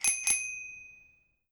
effect__bike_bell.wav